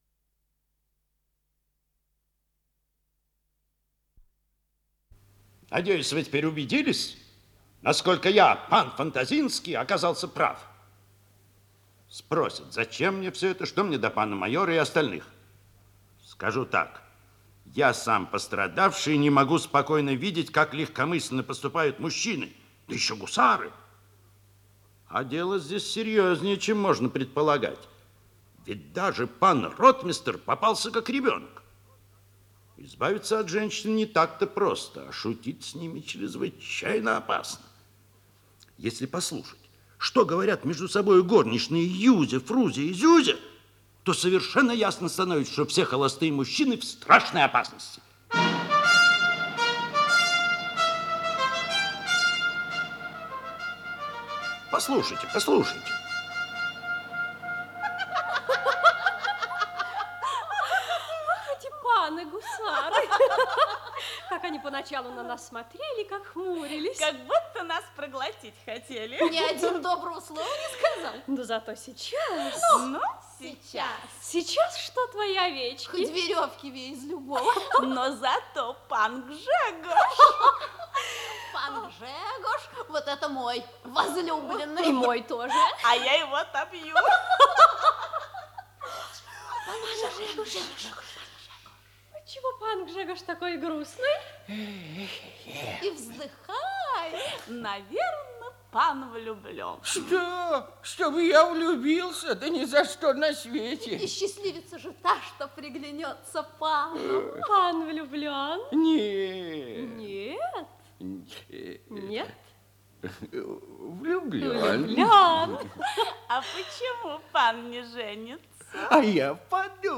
Исполнитель: Артисты театра им. Е. Вахтангова
Радиокомпозиция спектакля